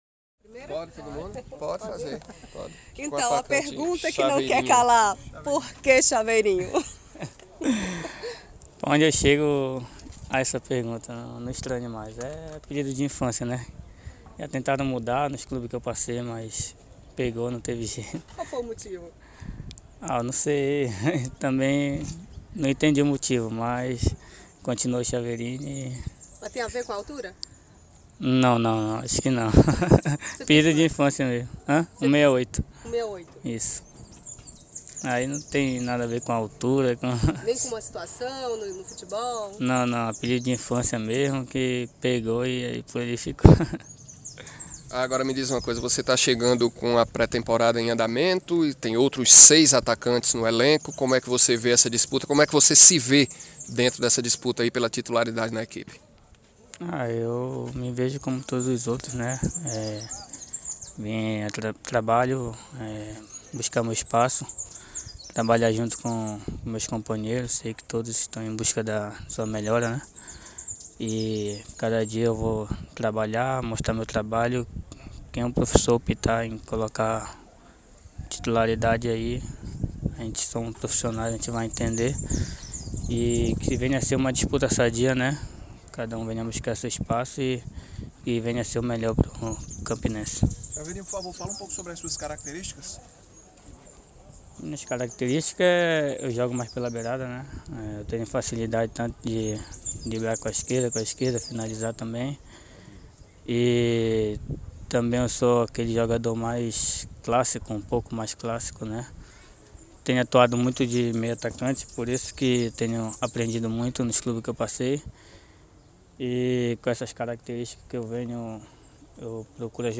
Ele garante que não importante como seja chamado, sua preocupação é jogar vem para ajudar o Campinense. Vamos ouvir a entrevista coletiva do jogador